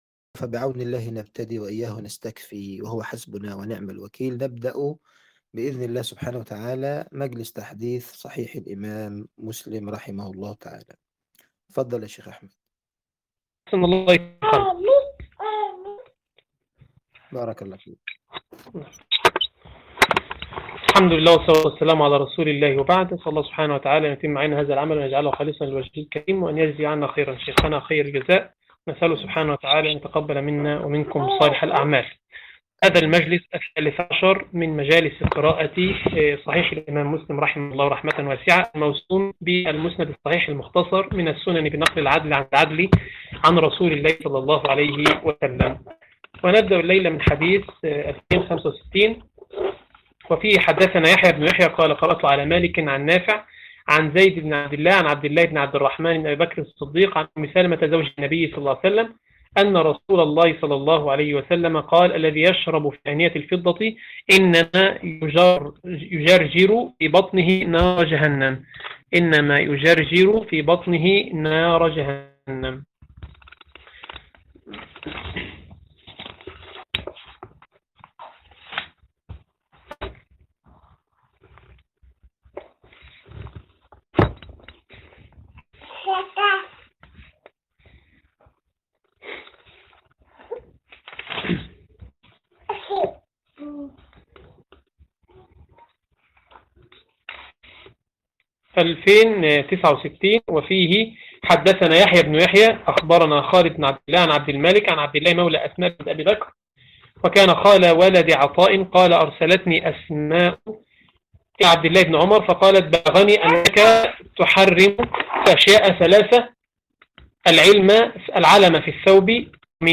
تفاصيل المادة عنوان المادة المجلس 13 - قراءة صحيح مسلم تاريخ التحميل الثلاثاء 12 اغسطس 2025 مـ حجم المادة 48.65 ميجا بايت عدد الزيارات 75 زيارة عدد مرات الحفظ 134 مرة إستماع المادة حفظ المادة اضف تعليقك أرسل لصديق